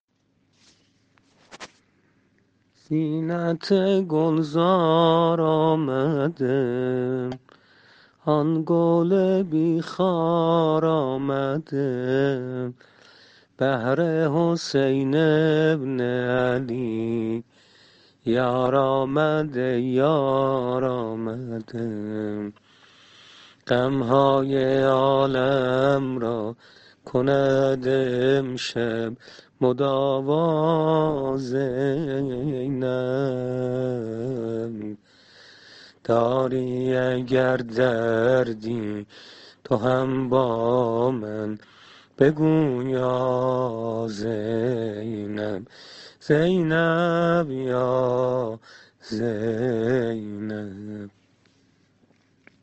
میلادیه حضرت زینب (س)